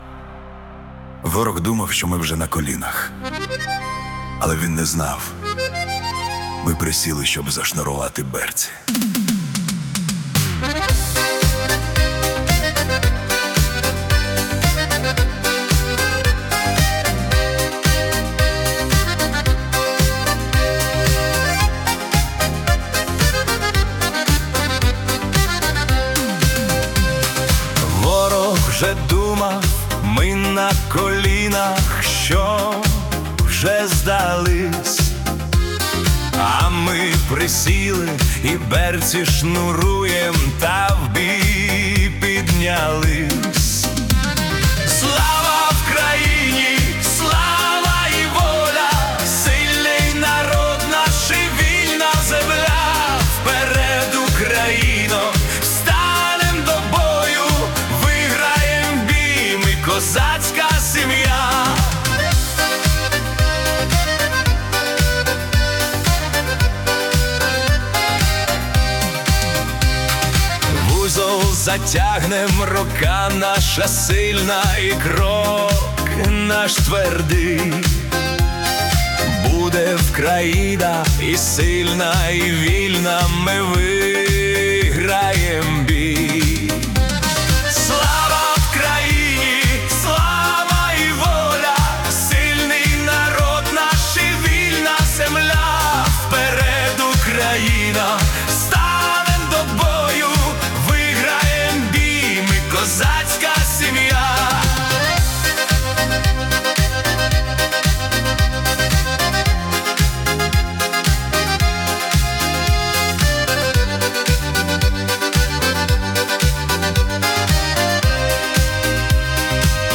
80s Upbeat Patriotic Estrada Пісня-заклик